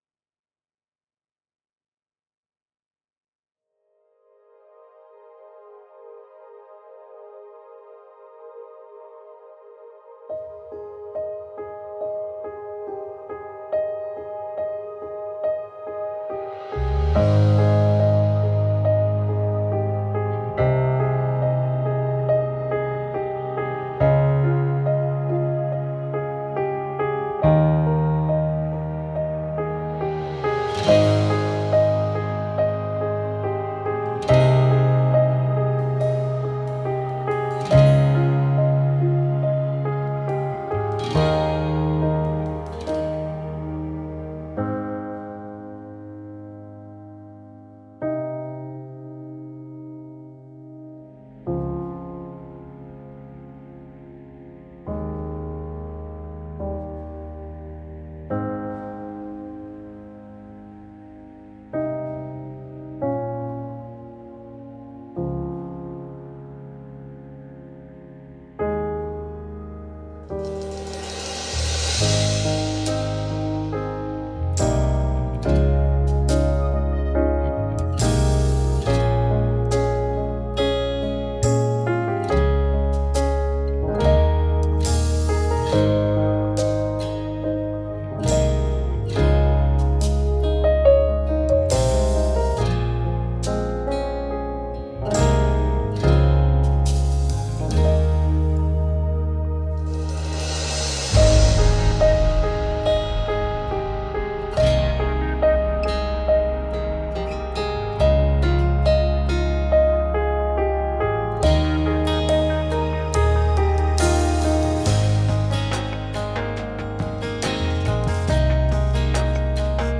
mp3 伴奏音樂
前奏 → 主歌 1 → 副歌 → 間奏 1 → 主歌 2 → 副歌(兩遍) → 間奏 2 → 橋段 → 副歌(兩遍)